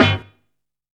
SWEET STAB.wav